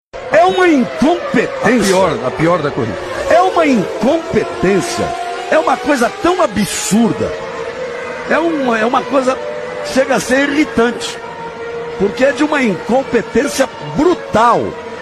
incompetencia galvao bueno Meme Sound Effect
Category: Sports Soundboard